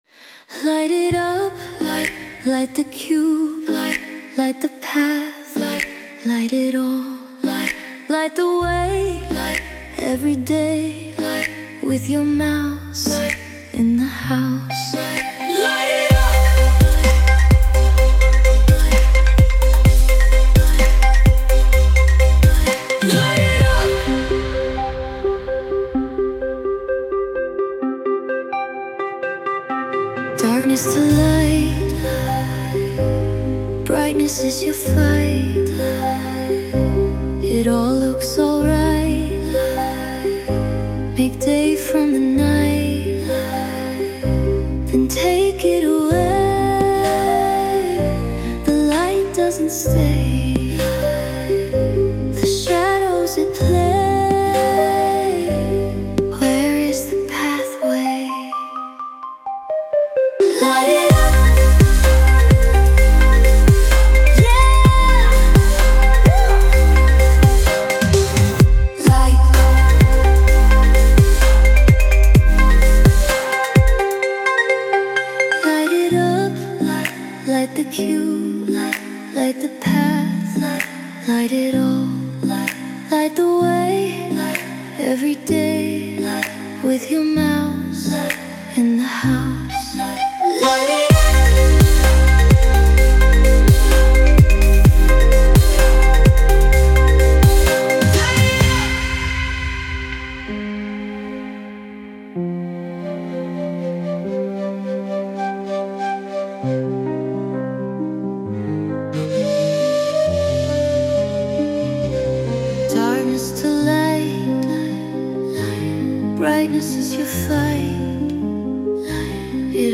Sound Imported : Liquid Horseradish
Sung by Suno